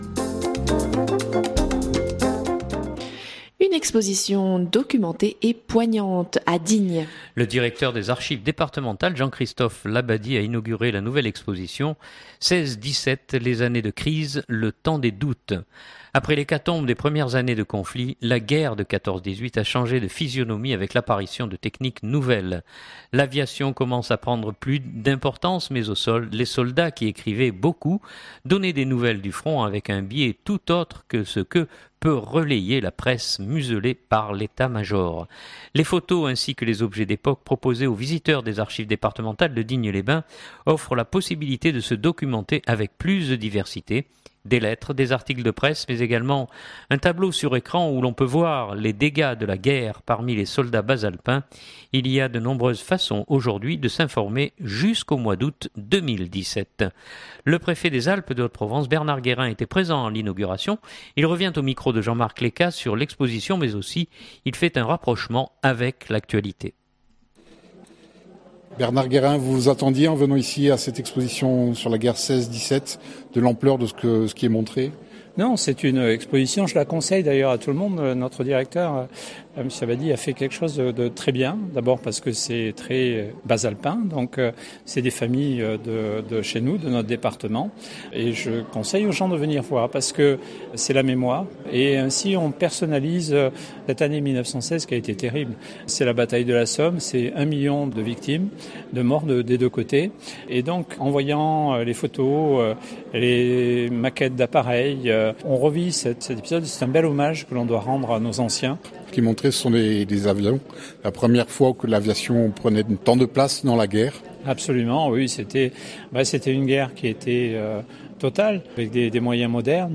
Le préfet des Alpes de Haute-Provence Bernard Guérin était présent à l’inauguration.